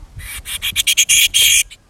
If you do not know what the calls a possum joey makes to its mother sounds like – please click the buttons below to hear the specific species audio recordings.
Brushtail Calling
BT-call.m4a